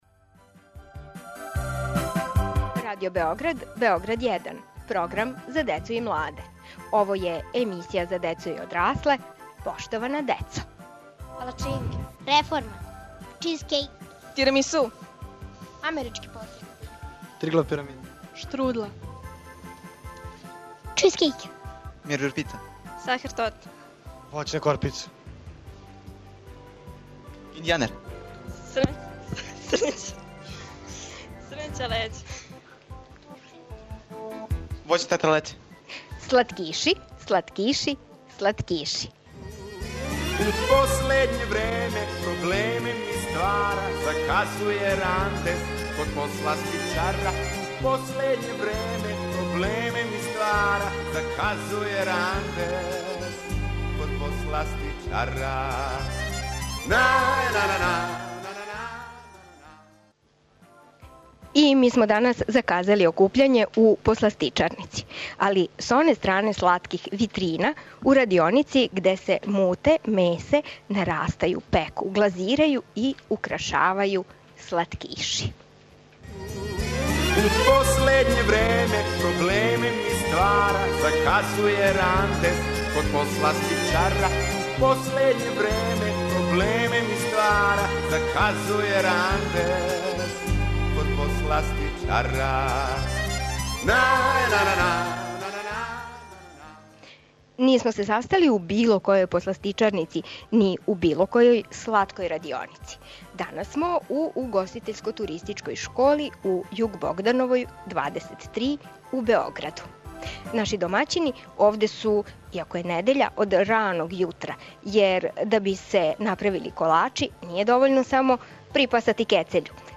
Домаћини су нам професори посластичарства и ученици посластичарског смера ове школе.